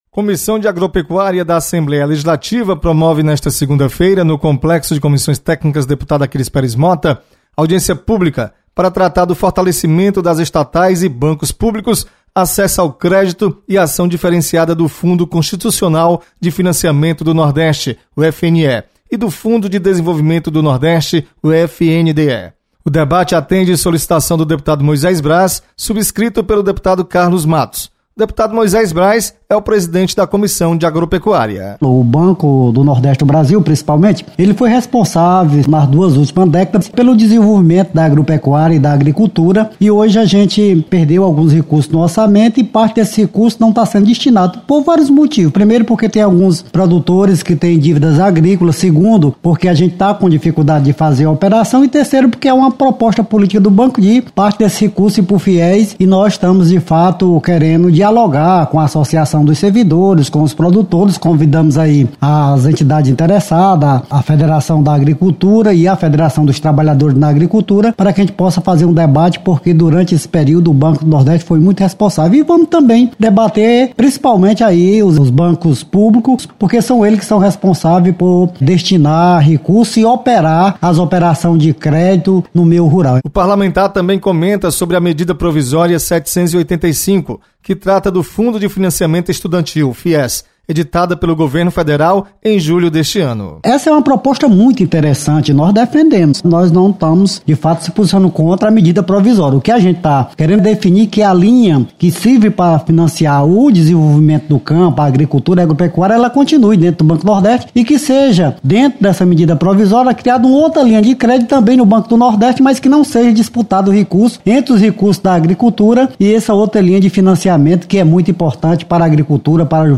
Fortalecimento do crédito ao agricultor é debatido em audiência pública. Repórter